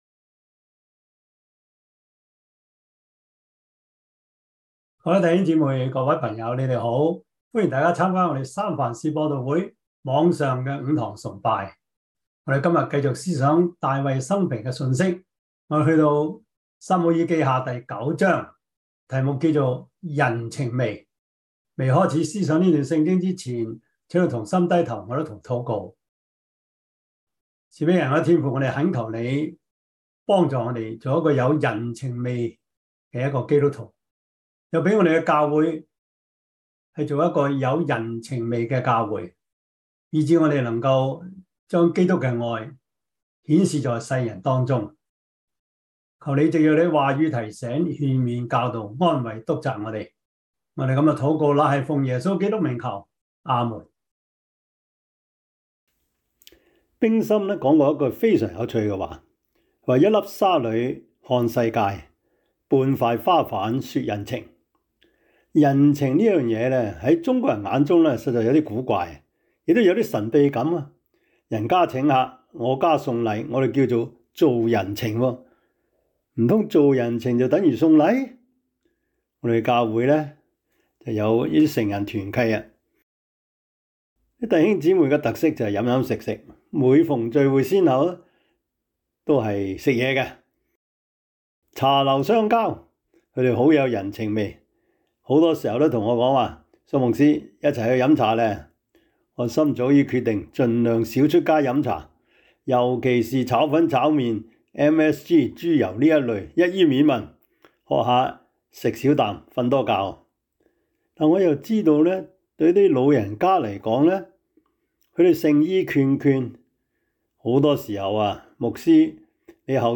2022 主日崇拜 Passage: 撒母耳記下 9 Service Type: 主日崇拜 撒母耳記下 9 Chinese Union Version
Topics: 主日證道 « 小心詐騙 快樂人生小貼士 – 第七課 »